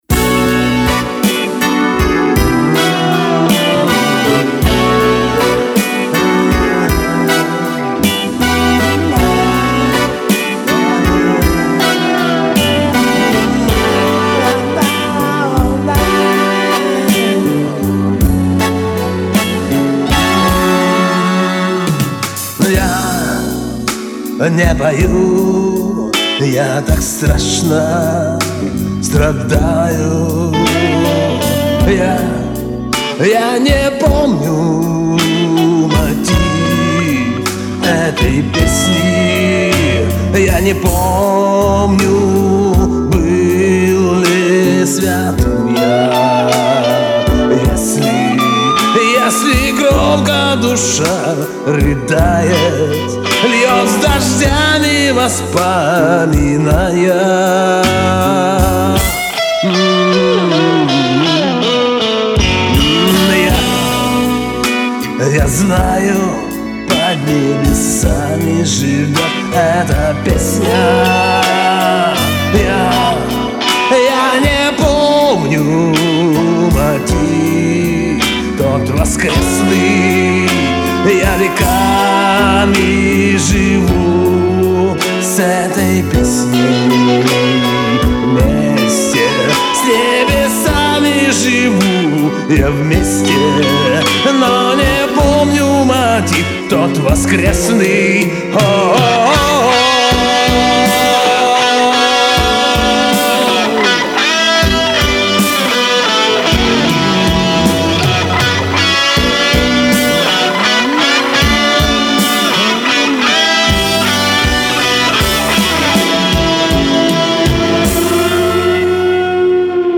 Поем блюз навеселе))) Комментарий соперника: